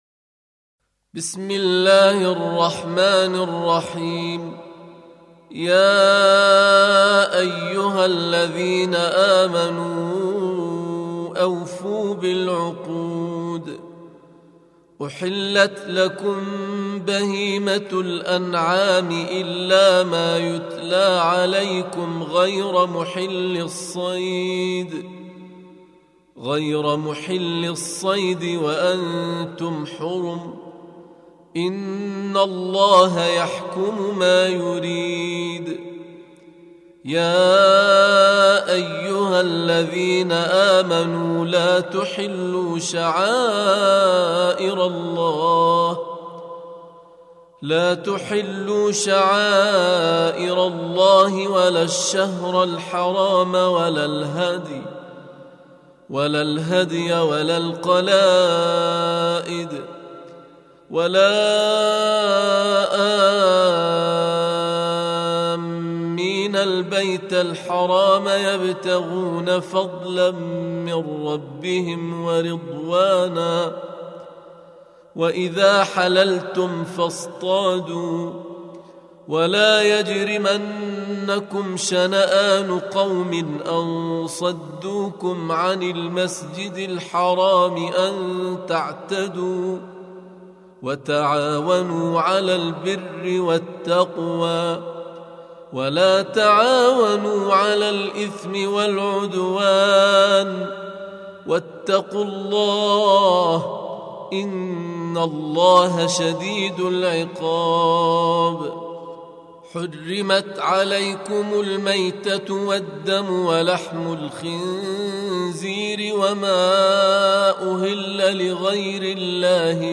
Surah Sequence تتابع السورة Download Surah حمّل السورة Reciting Murattalah Audio for 5. Surah Al-M�'idah سورة المائدة N.B *Surah Includes Al-Basmalah Reciters Sequents تتابع التلاوات Reciters Repeats تكرار التلاوات